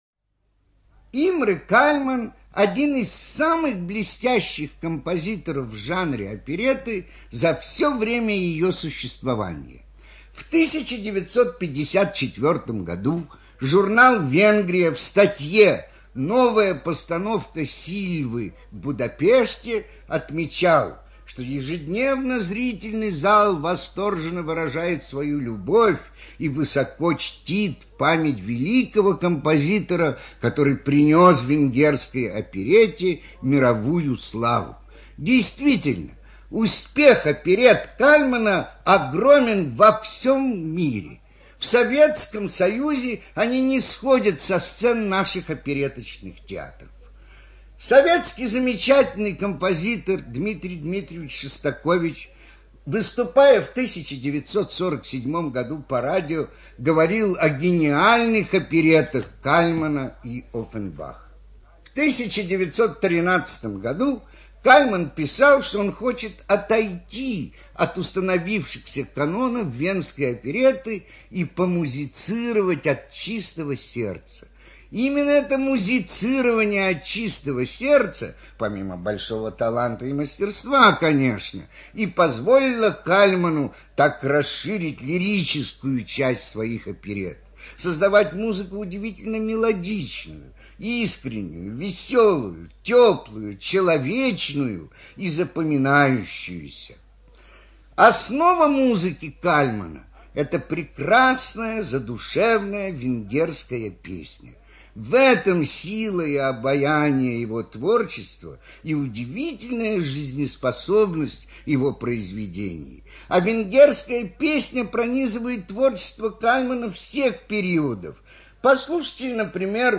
Радиопередача